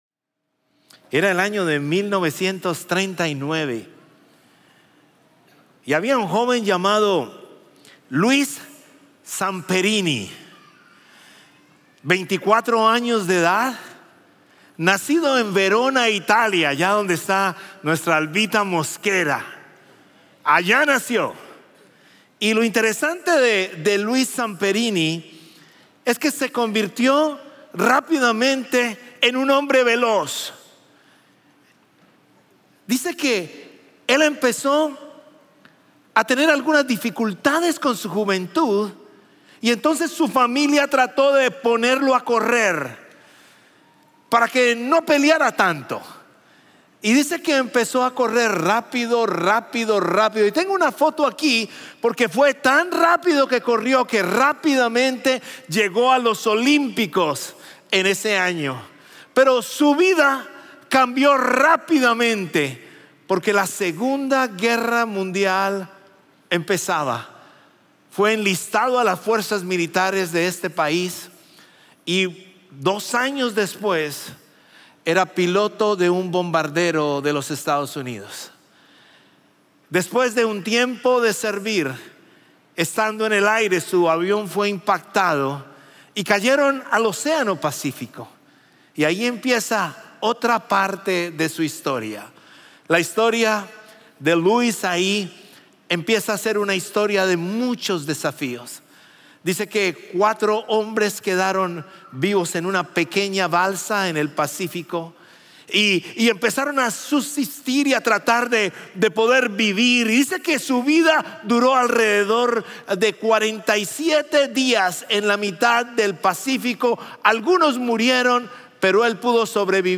Champion Forest Baptist Church...
Un mensaje de la serie "Más."